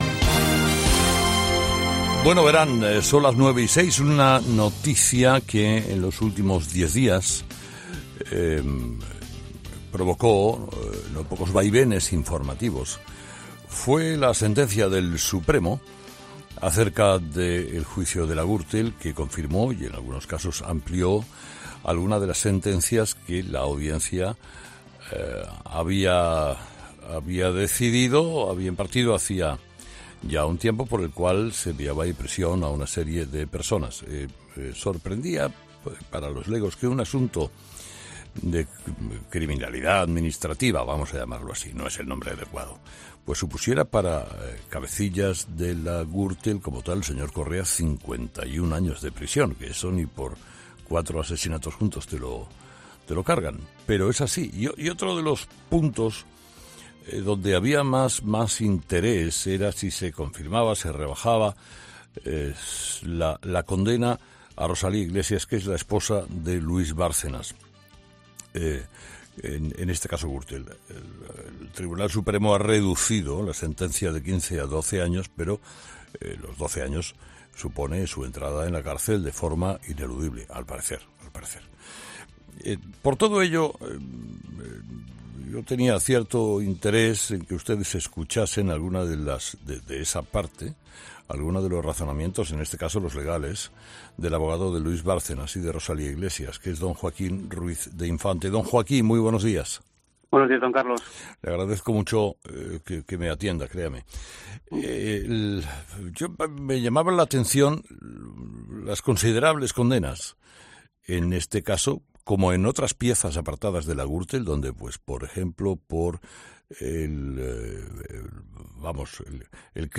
Esta mañana ha pasado por los micrófonos de 'Herrera en COPE' para valorar la sentencia del Tribunal Supremo y explicar los pasos que seguirá a partir de ahora.